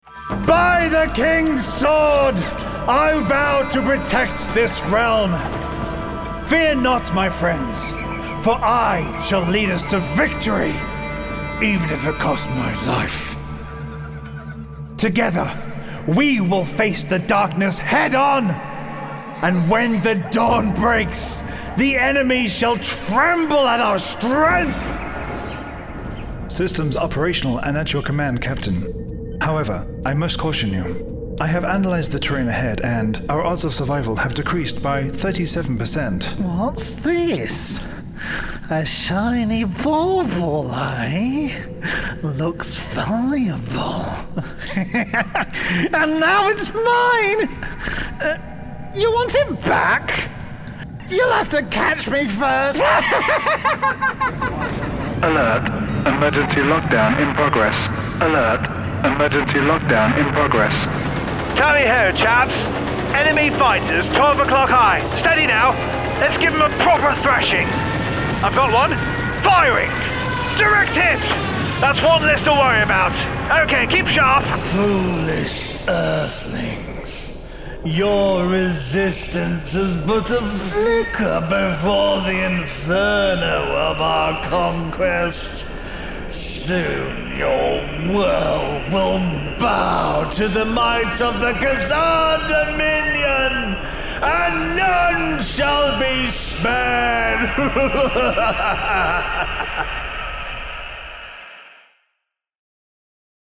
Male
English (British), English (Irish)
Adult (30-50)
Video Game / Animation Demo